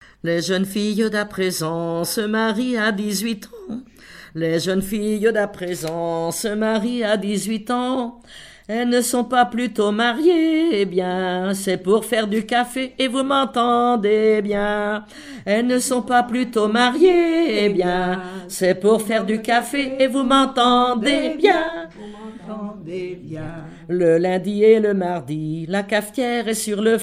Genre strophique
collectif de chanteuses de chansons traditionnelles
Pièce musicale inédite